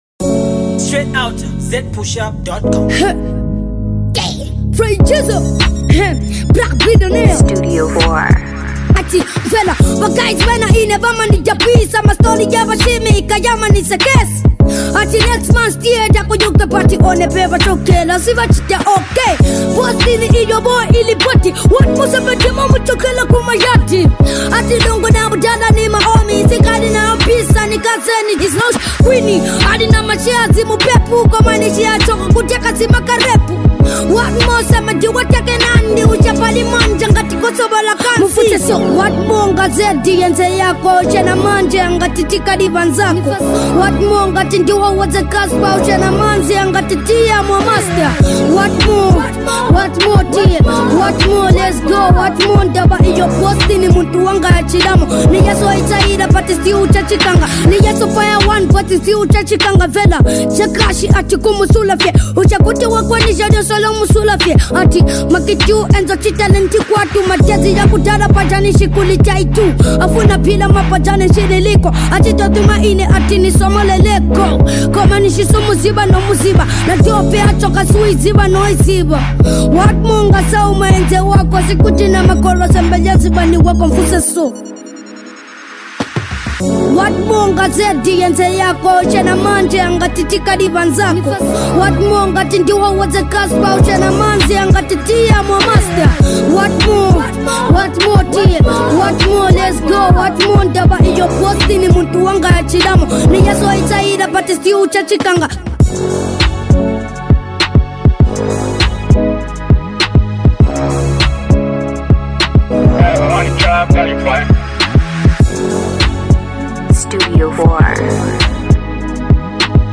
Young top trending rapper